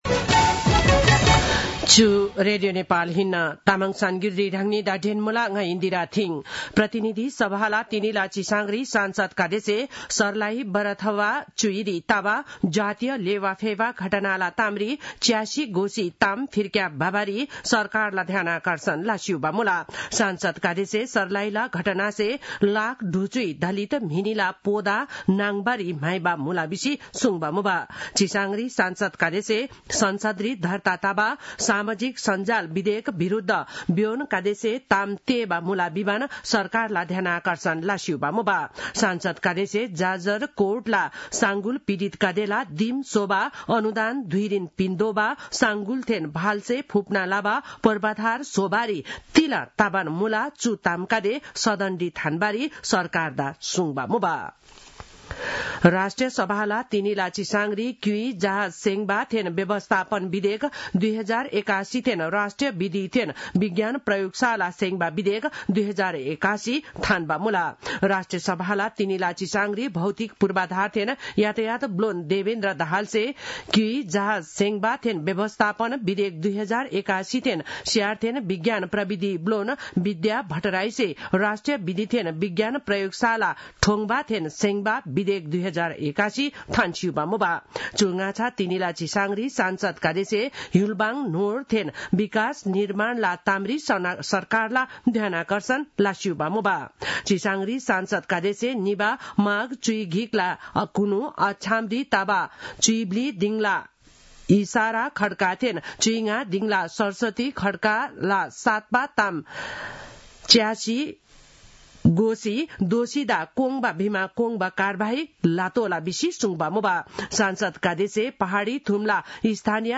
तामाङ भाषाको समाचार : २५ माघ , २०८१
Tamang-news-10-24.mp3